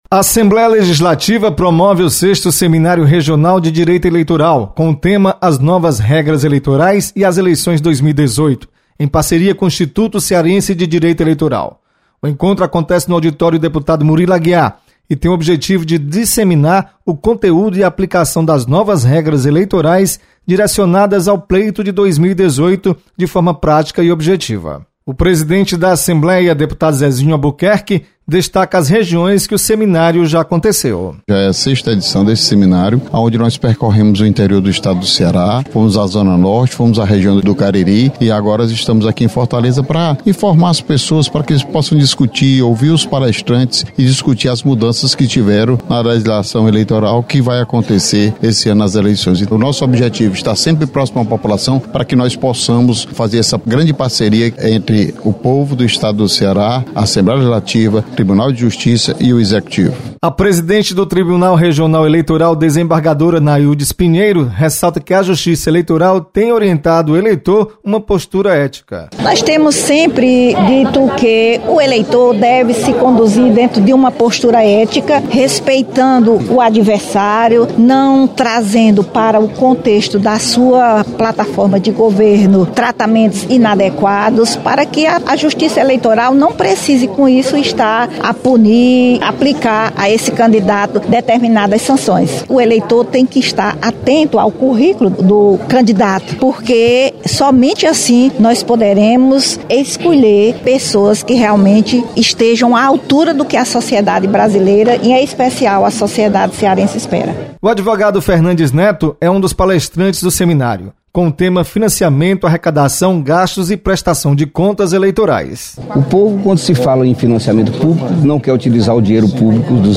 Deputado Zezinho Albuquerque abre oficialmente o Sexto Seminário Regional de Direito Eleitoral. Repórter